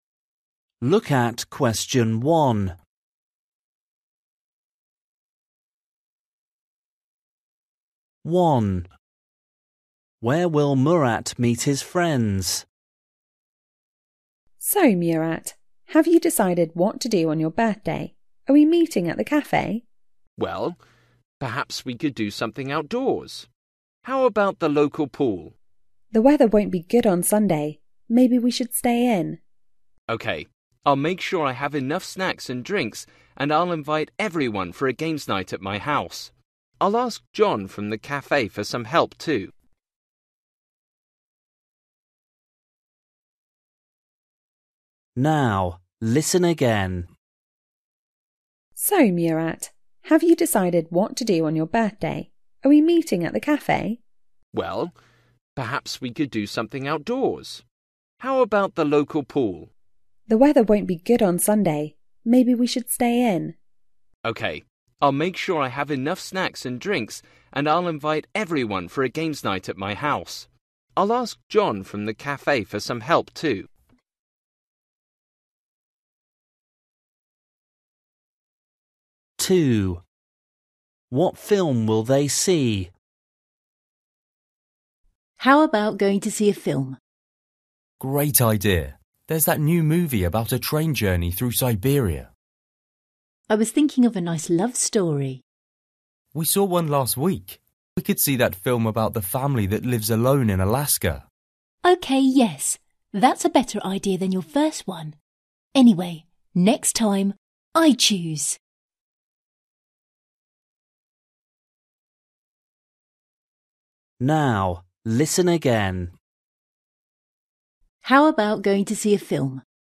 Listening: Conversations at home or in a shop